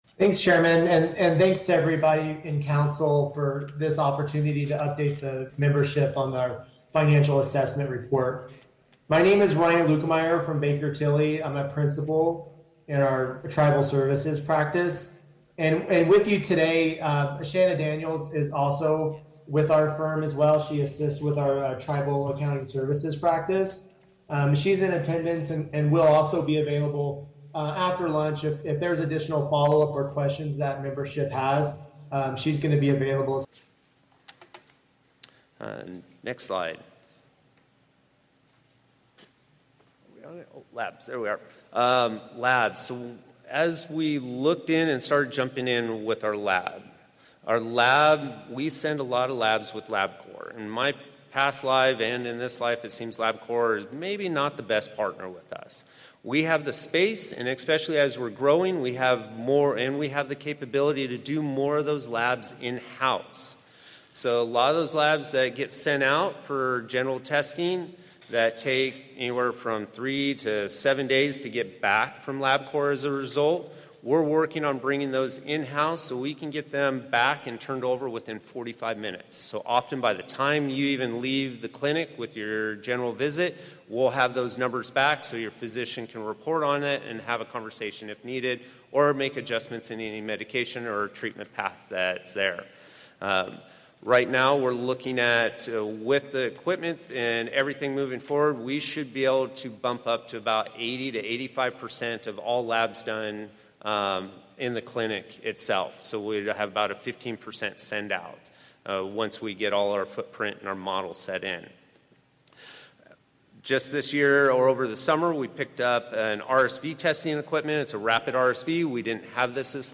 This hour starts with the Bakertily Financial Overview, (formerly known as Moss Adams). There was a break for lunch at the end of the Financial Overview.